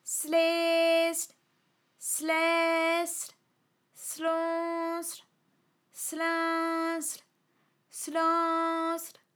ALYS-DB-001-FRA - First, previously private, UTAU French vocal library of ALYS